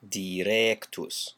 Ääntäminen
US : IPA : [də.ˈɹɛkt]